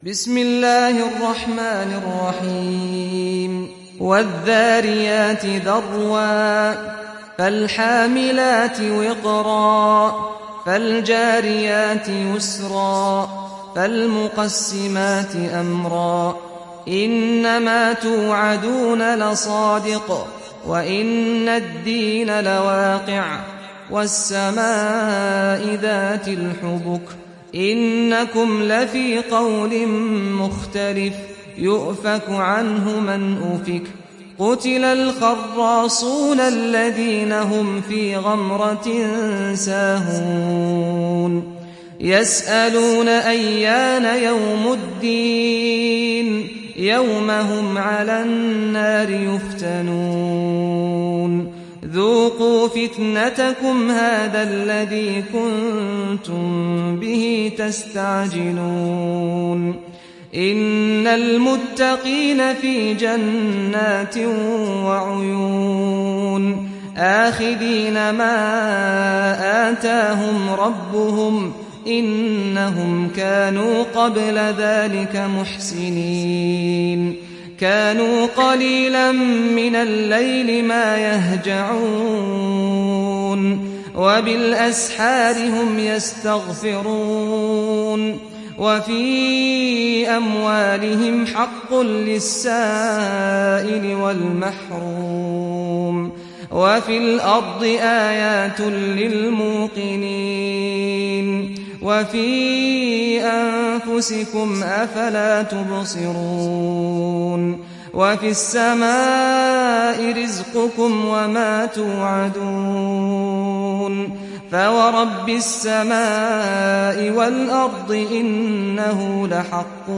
تحميل سورة الذاريات mp3 بصوت سعد الغامدي برواية حفص عن عاصم, تحميل استماع القرآن الكريم على الجوال mp3 كاملا بروابط مباشرة وسريعة